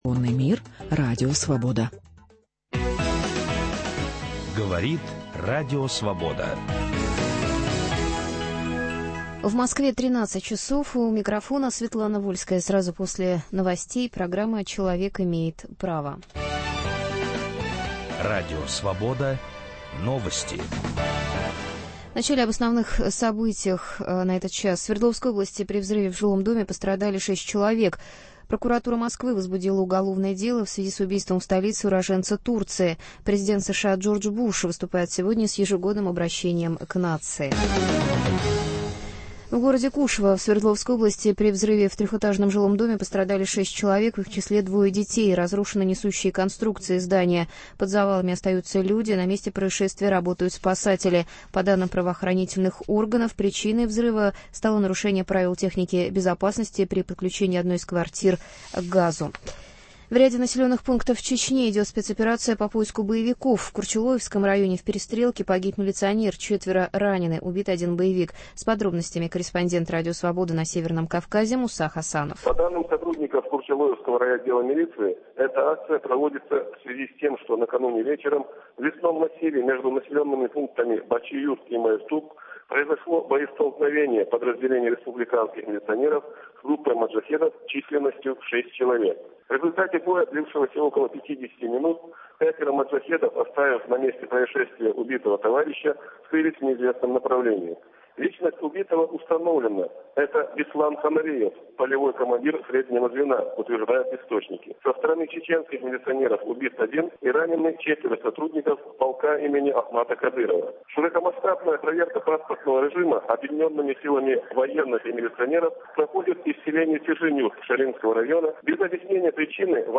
Участники передачи: председатель Федерации Независимых Профсоюзов России Михаил Шмаков